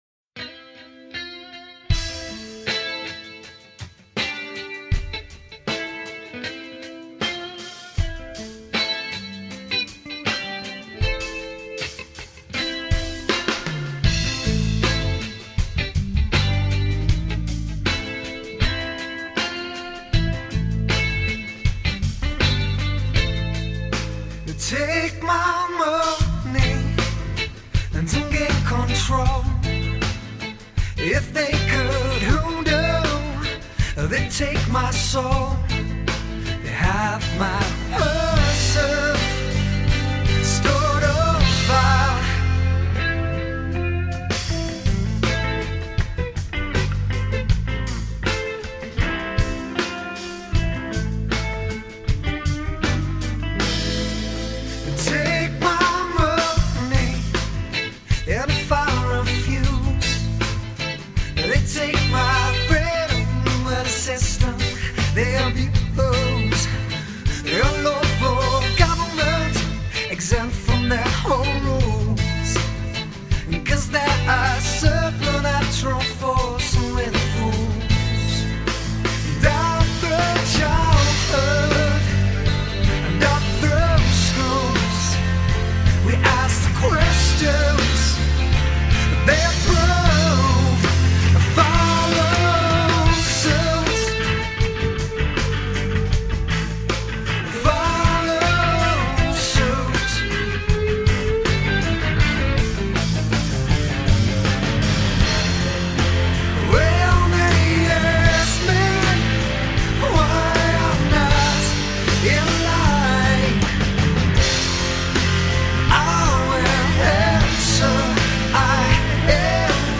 four piece rock band